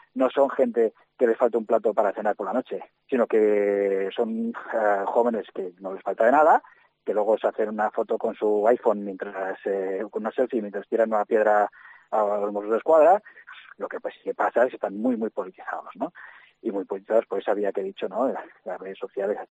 El profesor de Ciencias Políticas